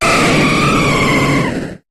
Cri de Lugia dans Pokémon HOME.